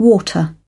İngiliz Aksanı:  Voa-ta
UK  /ˈwɔː.tər/
British Accent: